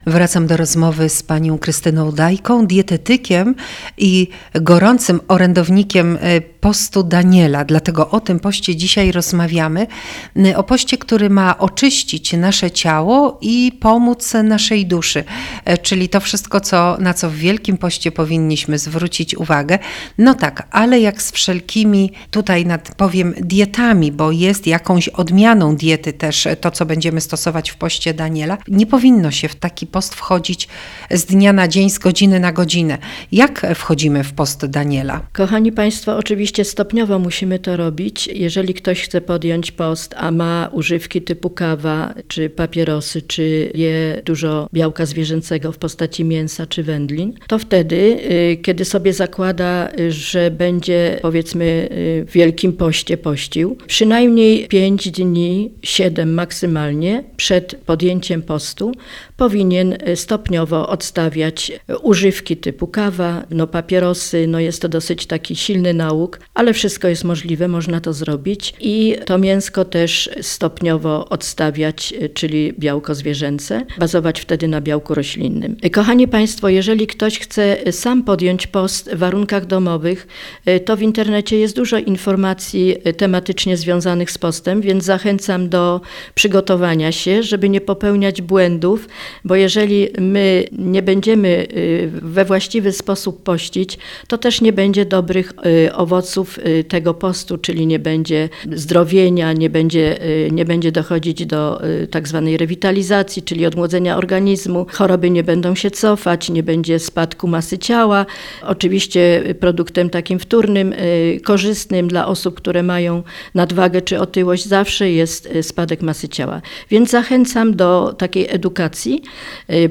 Nagranie, Wywiad
Zachęcamy do przesłuchania wywiadu w Radiu eM Katowice, aby poznać więcej szczegółów i odkryć, jak post może wpłynąć na Twoje życie!